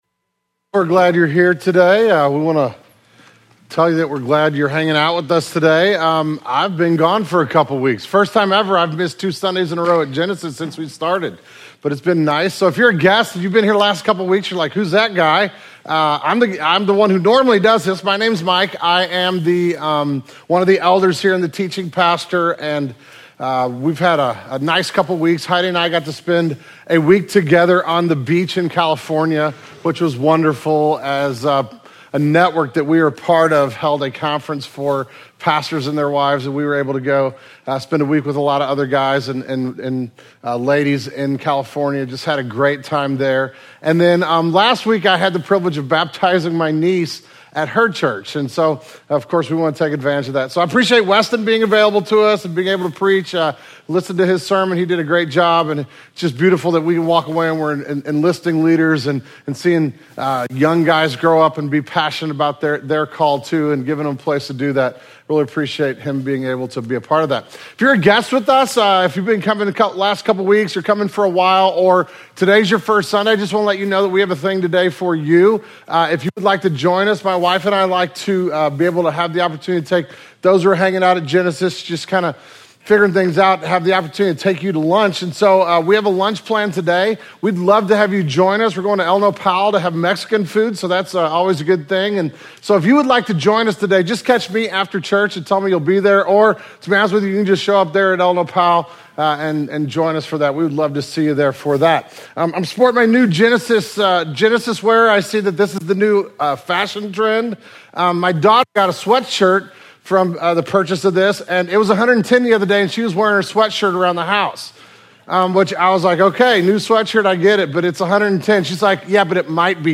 The July 2012 Sermon Audio archive of Genesis Church.